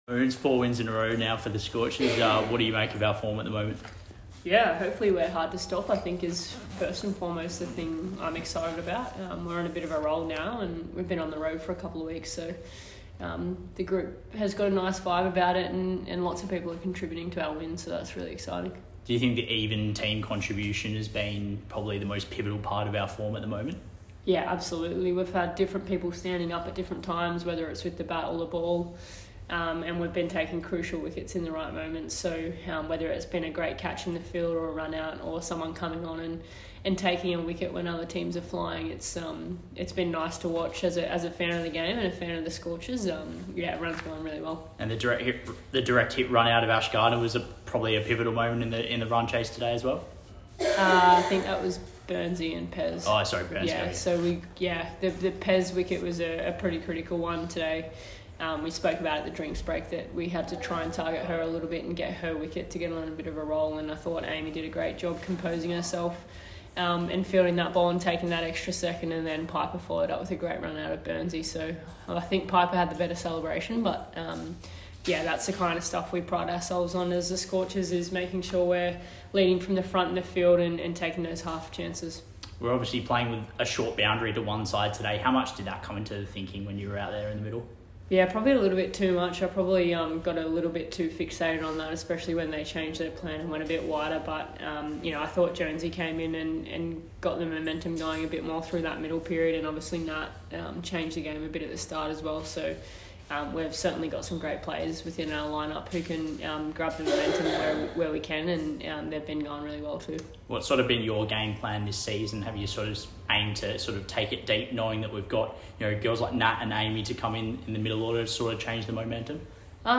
Player of the Match Beth Mooney (91 off 57) spoke after the Scorchers 61-run win over Sydney Sixers at North Sydney Oval today.